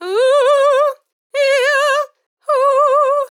TEN VOCAL FILL 11 Sample
Categories: Vocals Tags: dry, english, female, fill, sample, TEN VOCAL FILL, Tension
POLI-VOCAL-Fills-100bpm-A-11.wav